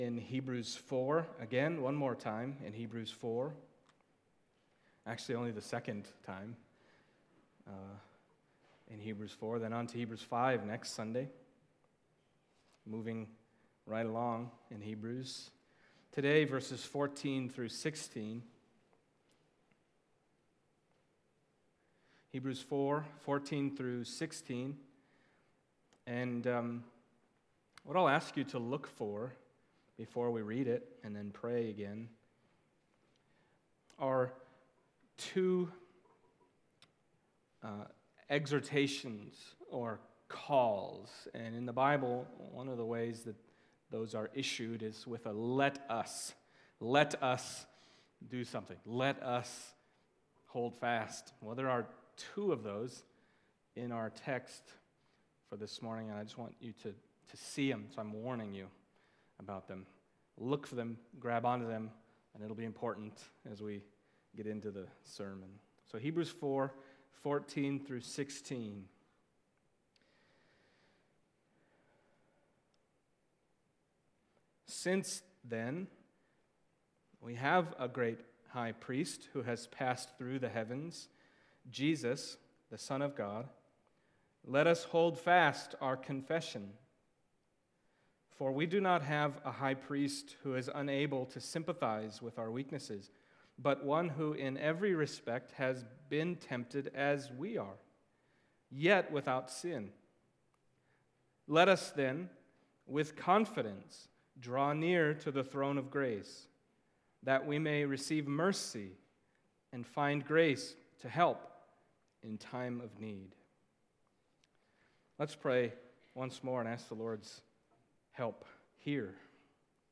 2013 The Throne of Grace Preacher
Hebrews Passage: Hebrews 4:14-16 Service Type: Sunday Morning Hebrews 4